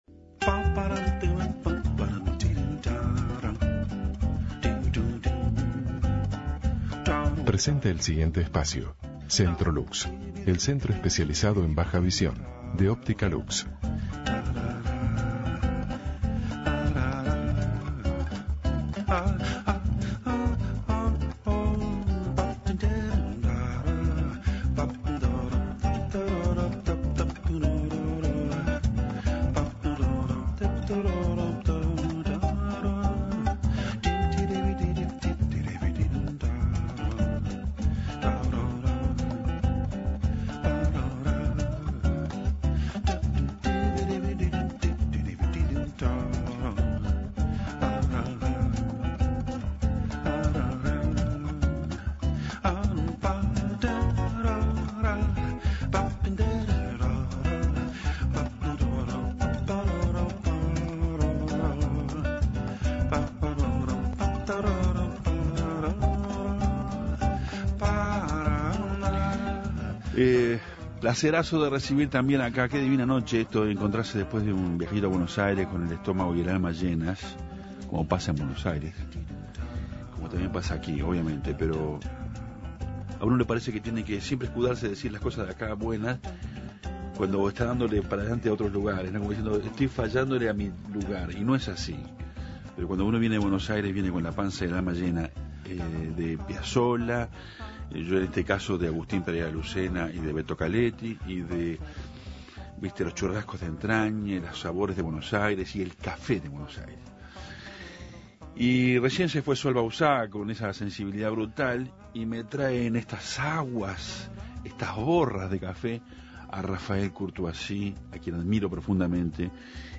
Dialogó sobre los talleres de cultura y creción. Escuche la entrevista.